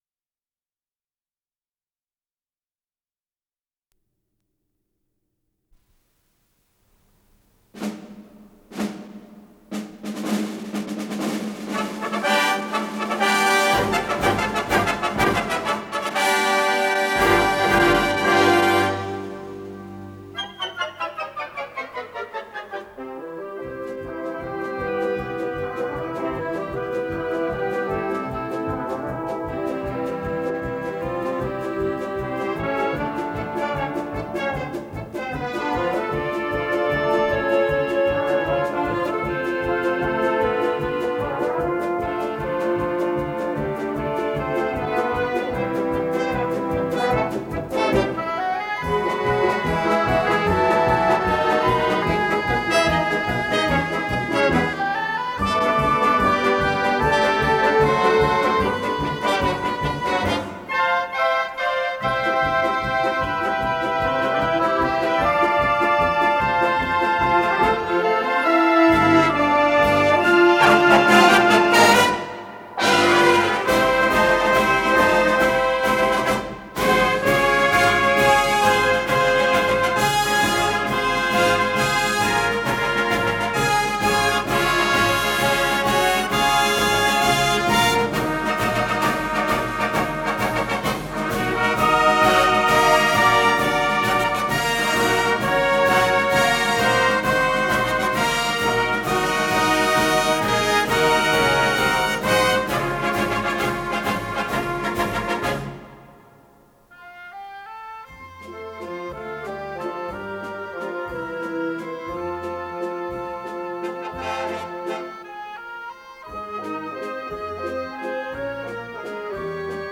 с профессиональной магнитной ленты
Скорость ленты38 см/с
Тип лентыСвема Тип А4620-6Р
МагнитофонМЭЗ-109А